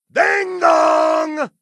ding-dong